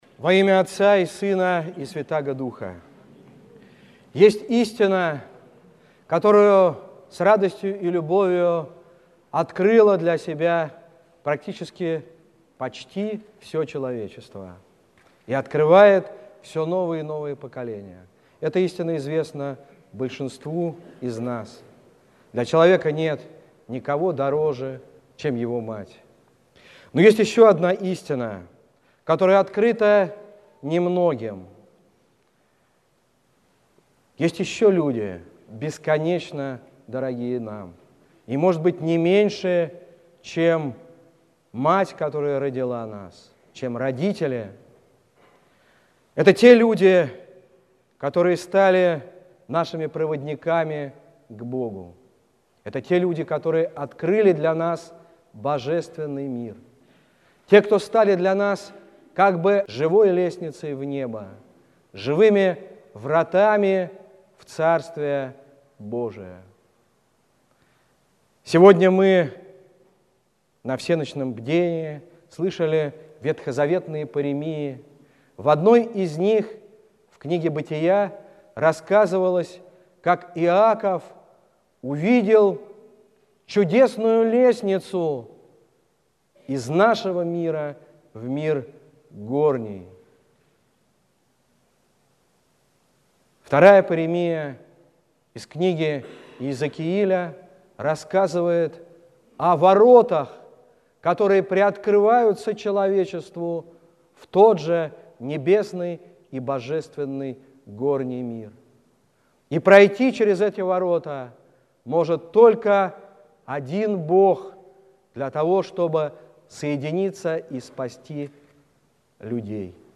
Слово накануне Рождества Пресвятой Богородицы
Епископ Егорьевский Тихон (Шевкунов)